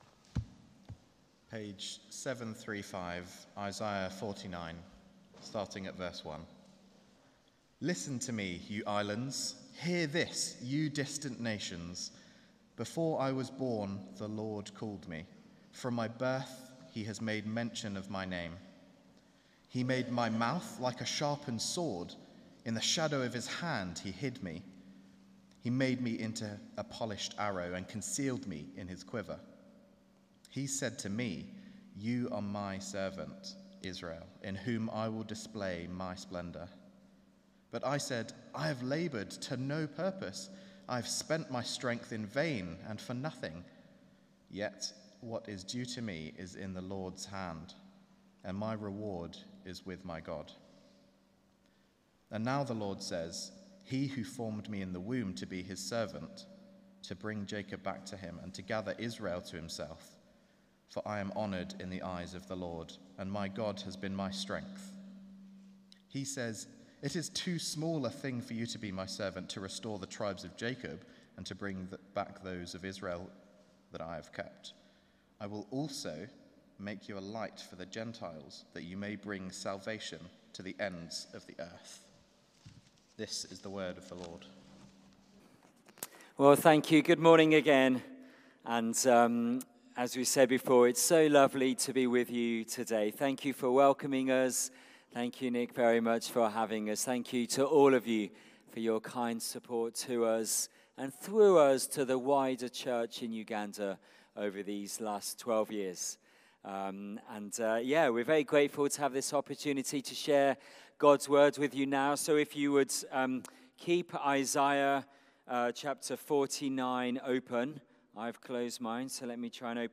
Passage: Isaiah 49:1-6 Series: Mission Partner Visit Theme: Sermon